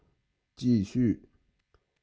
口音（男声）